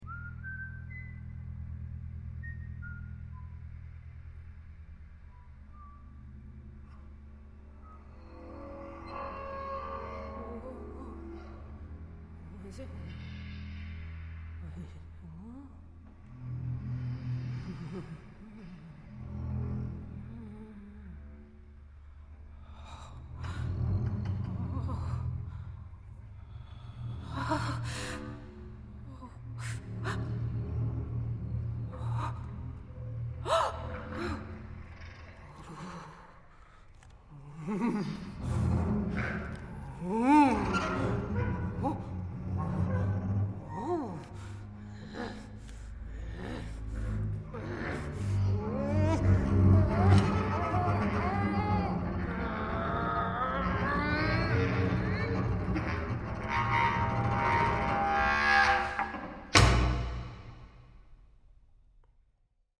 • Genres: Classical, Vocal